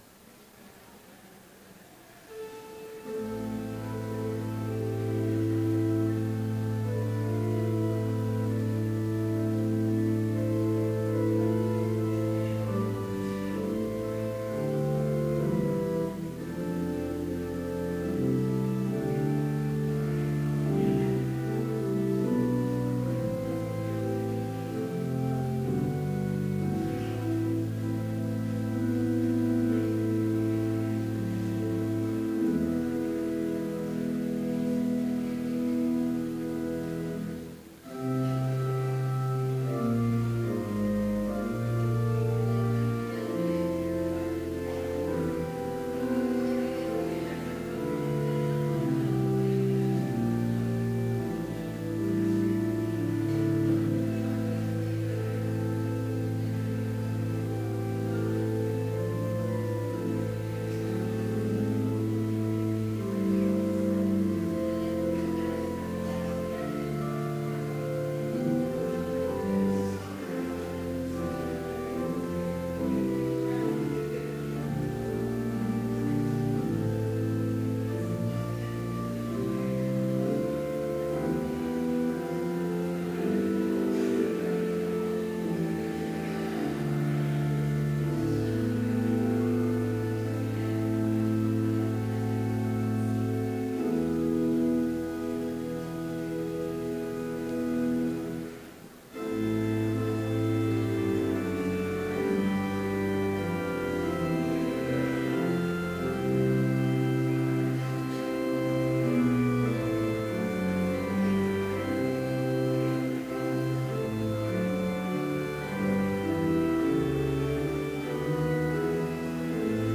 Complete service audio for Chapel - December 15, 2015